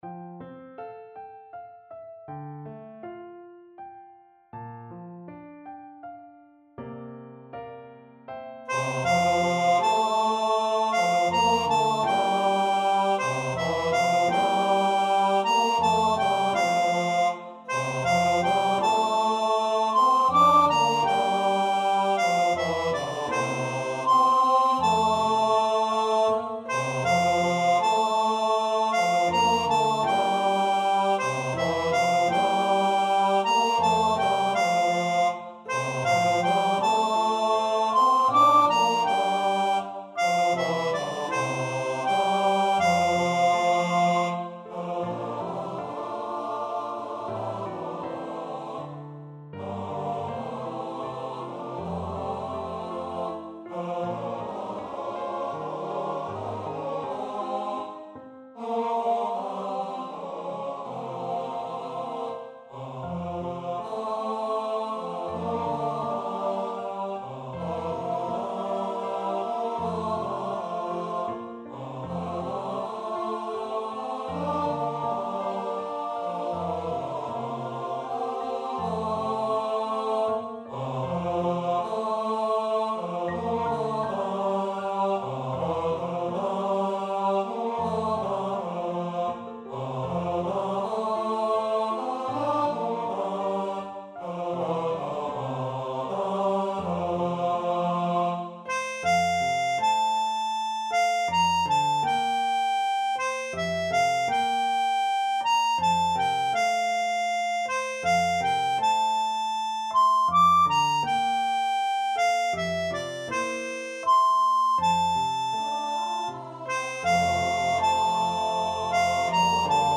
Last of the Summer Wine TTBB.mp3